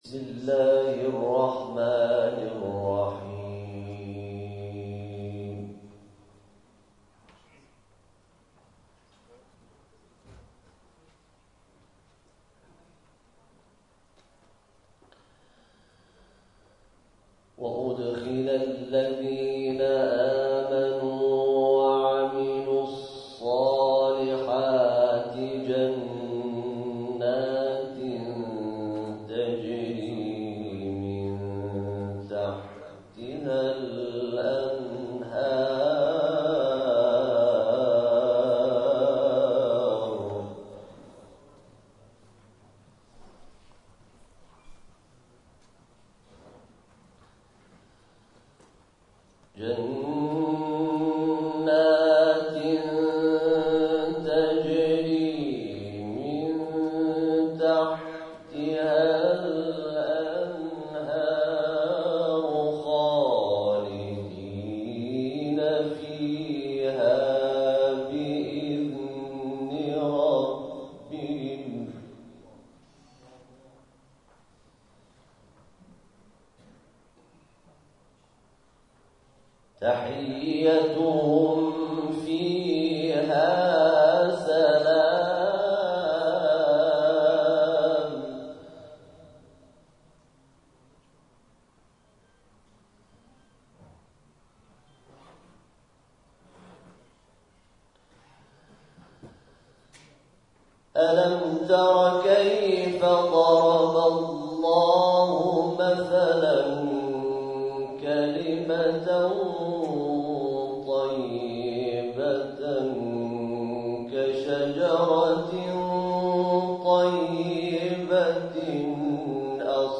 گروه جامعه: مراسم افتتاحیه چهارمین همایش قرآن، عترت و سلامت ویژه جامعه پزشکی با حضور آیت الله موحدی کرمانی، علی اکبر ولایتی، علیرضا مرندی و علیرضا زالی برگزار شد.